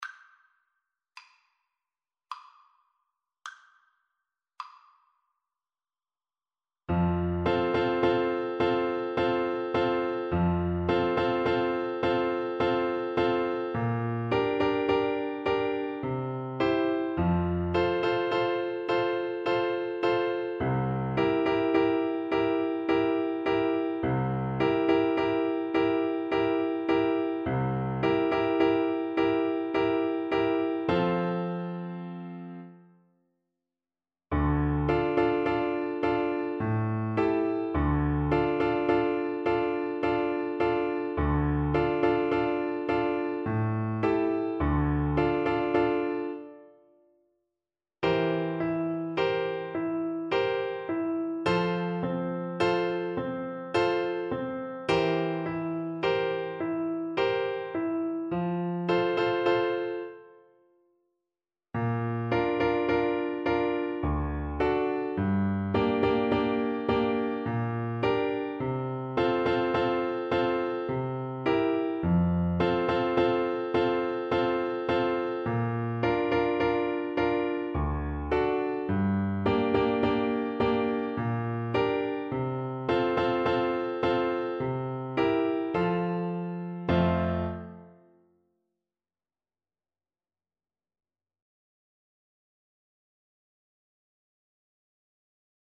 Clarinet
3/4 (View more 3/4 Music)
Maestoso
Traditional (View more Traditional Clarinet Music)